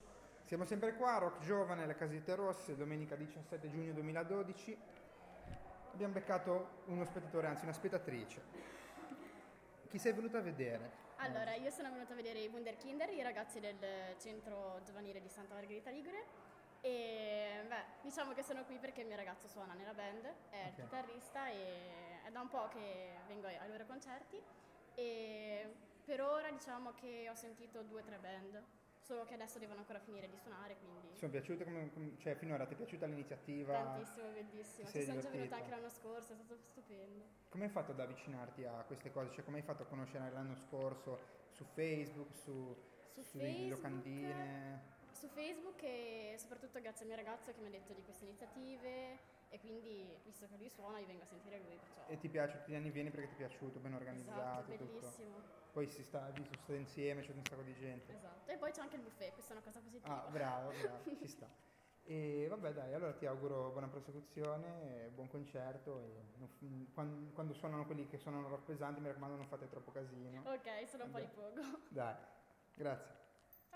Studente intervista